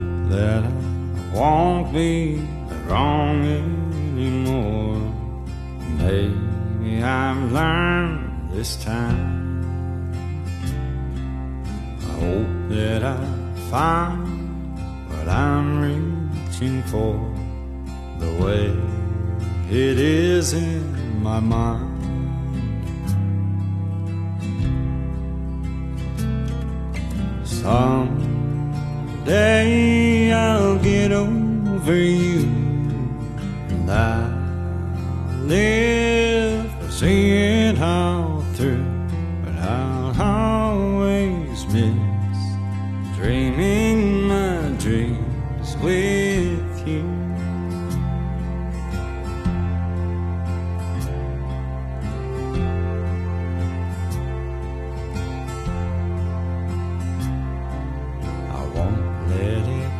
classic country songs
bluegrass song
mostly sad country songs